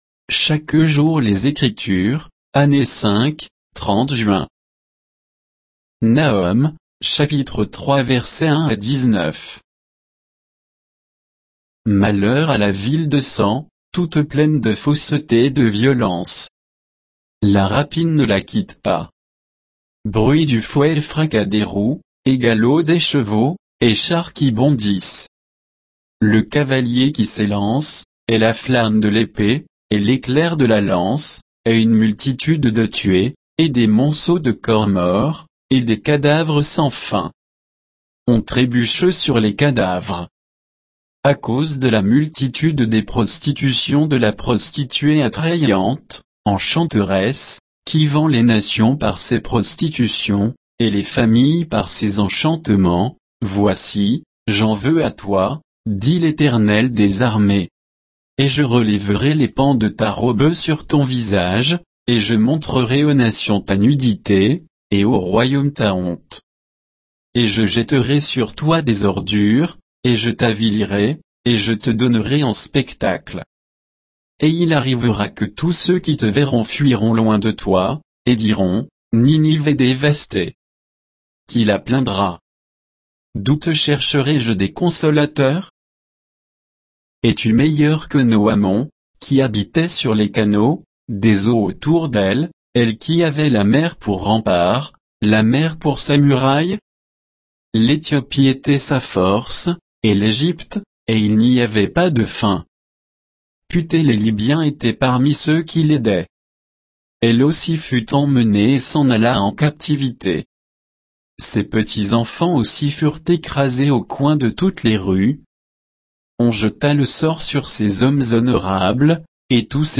Méditation quoditienne de Chaque jour les Écritures sur Nahum 3, 1 à 19